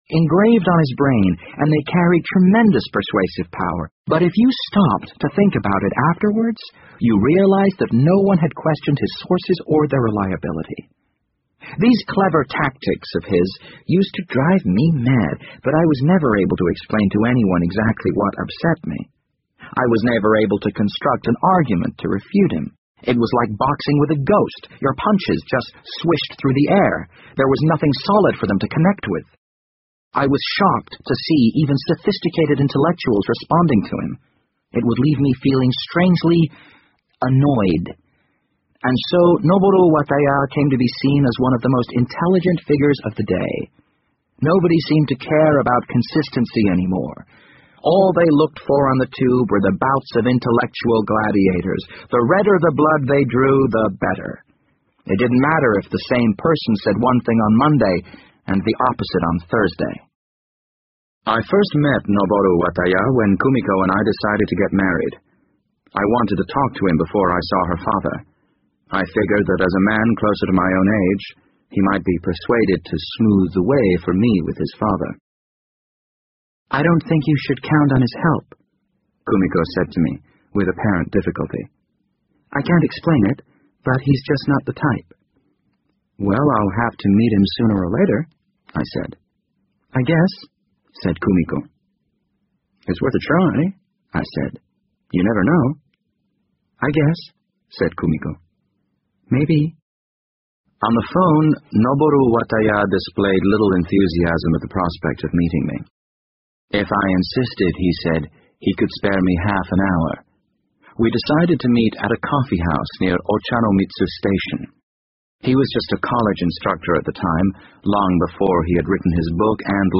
BBC英文广播剧在线听 The Wind Up Bird 38 听力文件下载—在线英语听力室